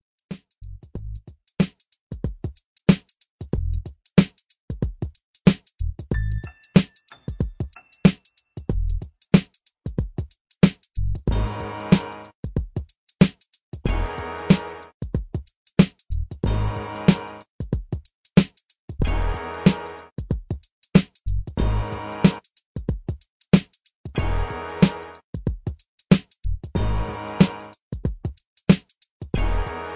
Mysterious East Coast Hip Hop